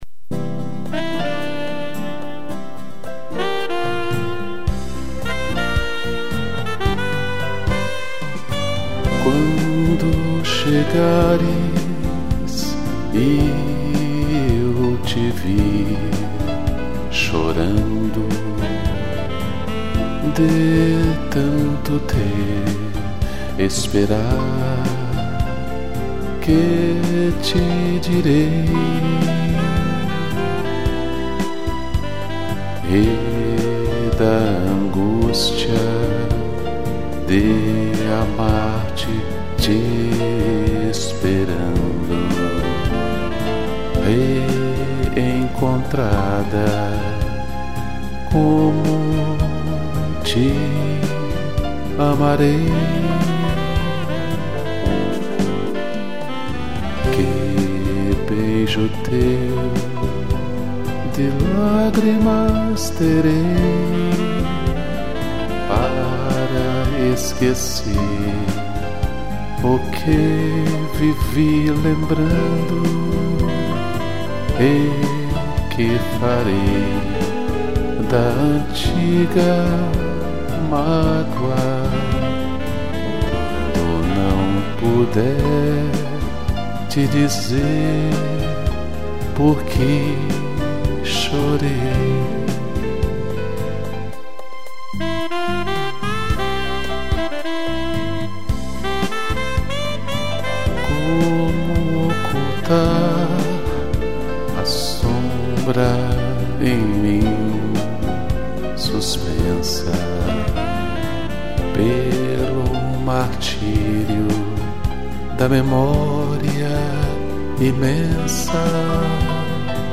piano, strings e sax